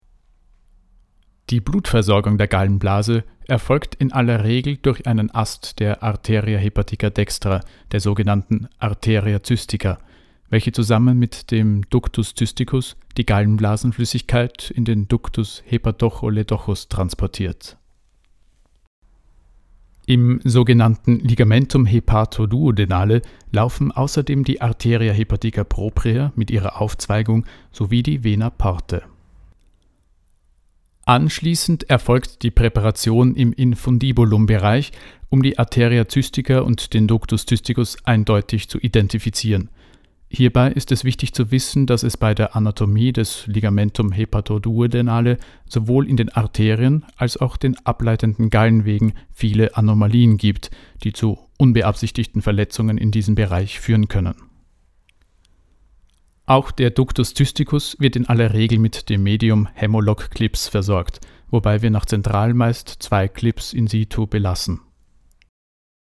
Wach, lebendig, resonierend, anpassungsfĂ€hig, dynamisch, gelassen
Sprechprobe: eLearning (Muttersprache):
Medizinische Sprachprobe - Reichl und Partner.mp3